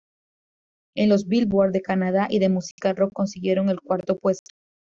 Pronunciado como (IPA)
/ˈkwaɾto/